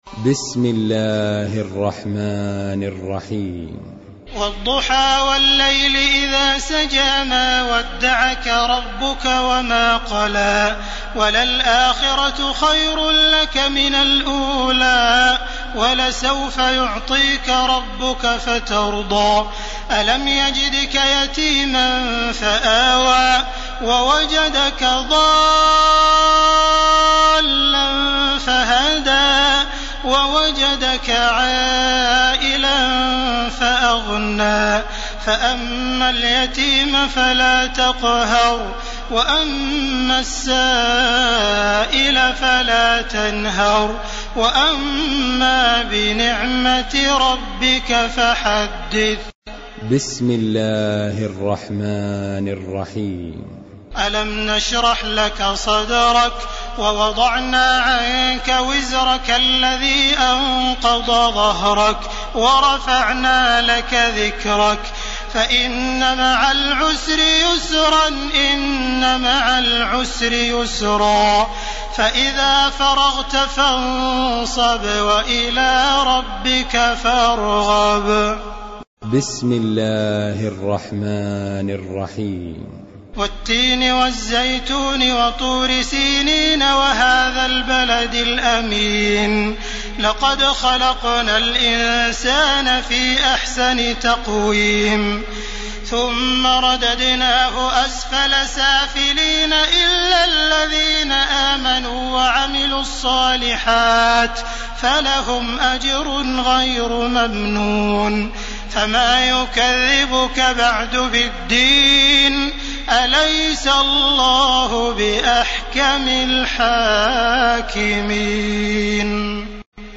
تراويح ليلة 29 رمضان 1431هـ من سورة الضحى الى الناس Taraweeh 29 st night Ramadan 1431H from Surah Ad-Dhuhaa to An-Naas > تراويح الحرم المكي عام 1431 🕋 > التراويح - تلاوات الحرمين